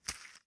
揉纸/扔纸 " 扔纸篓3
描述：我把一张纸扔进垃圾箱。 以Zoom Q4 WAV格式录制。
Tag: 纸揉碎 滨折腾 报纸 弄皱 起皱 折腾 沙沙 沙沙 纸折腾 纸在斌 压皱